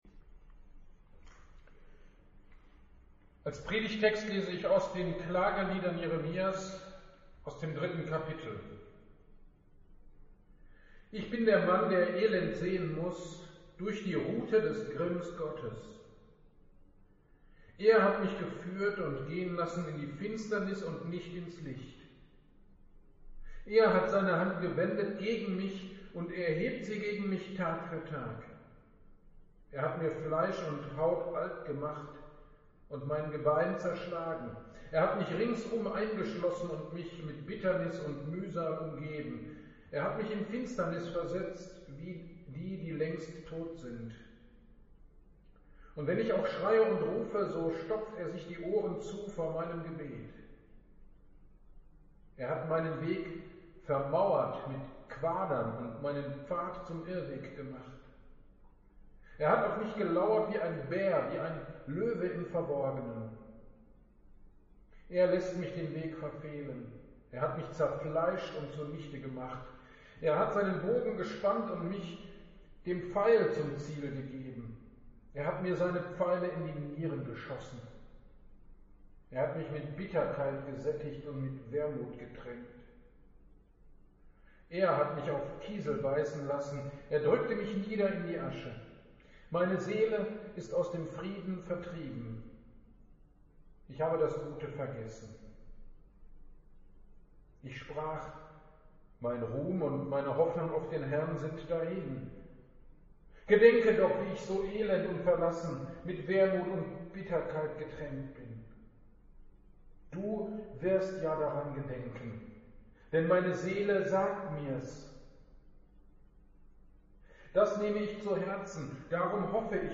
GD am 13.11.22 (Volkstrauertag) Predigt zu Klagelieder 3